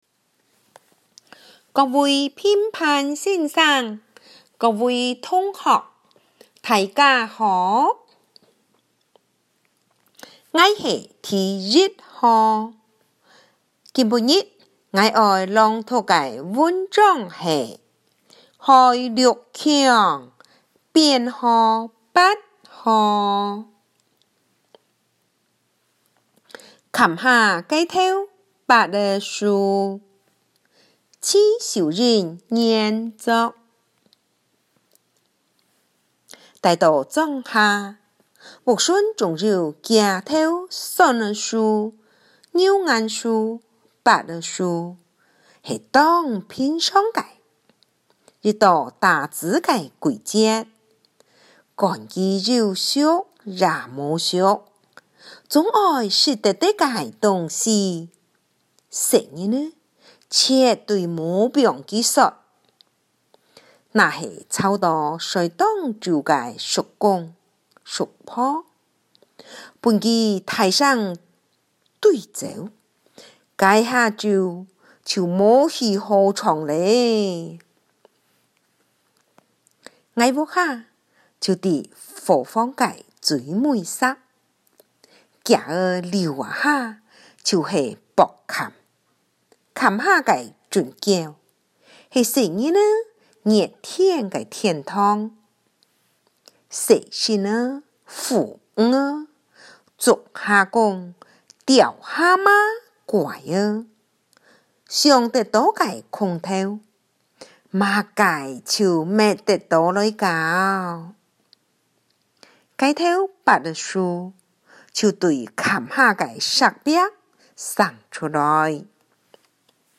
112 學年度嘉義市民生國中語文競賽【客家語】朗讀文章(含語音檔)